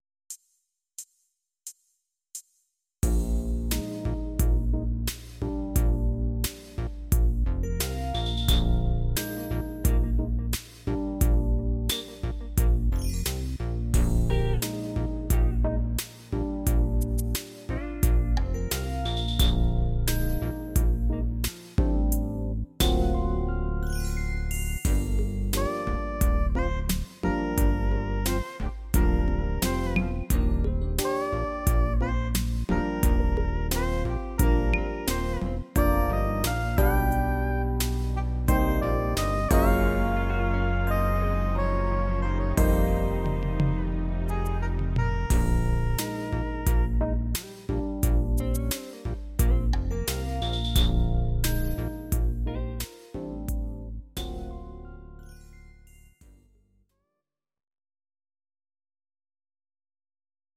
Audio Recordings based on Midi-files
Jazz/Big Band, Instrumental, 1990s